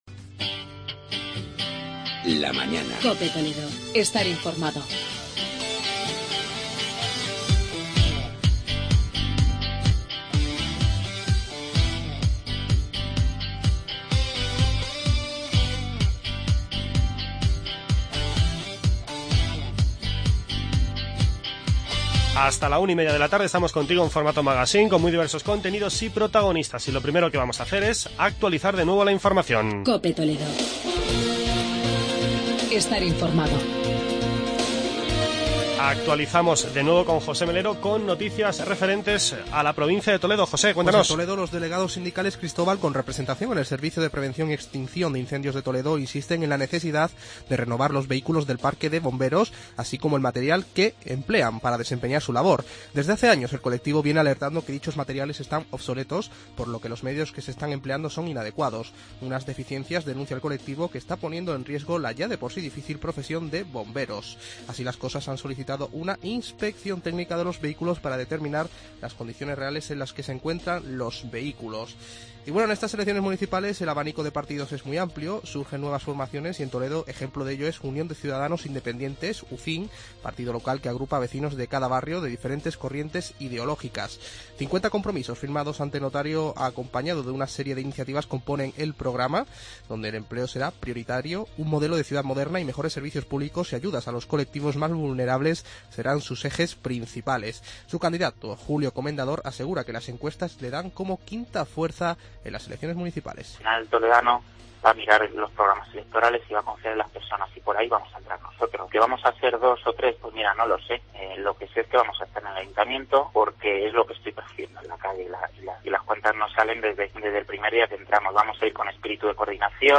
Charlamos con el alcalde de Talavera, Jaime Ramos